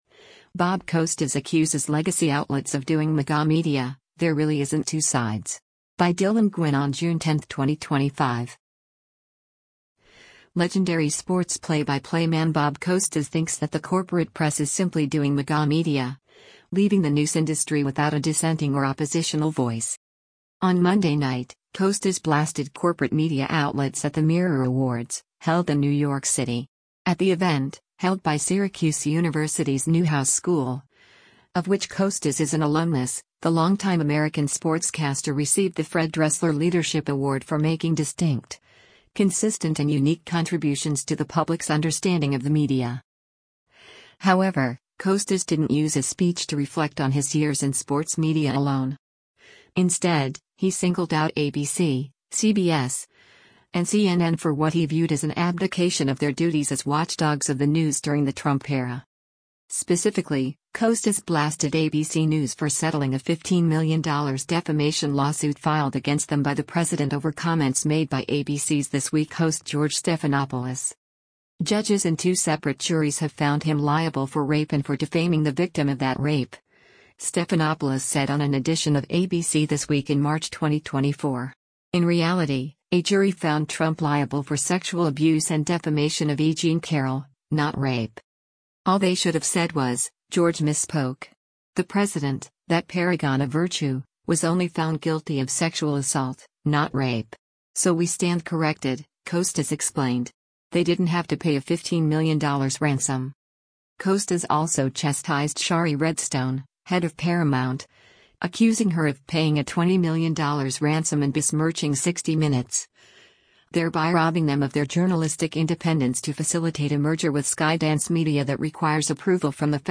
On Monday night, Costas blasted corporate media outlets at the Mirror Awards, held in New York City.